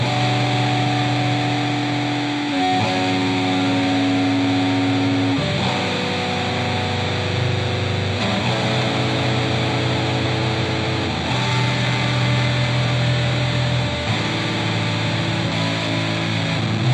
标签： 85 bpm Rap Loops Drum Loops 2.85 MB wav Key : Unknown
声道立体声